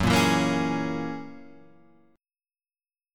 F# 9th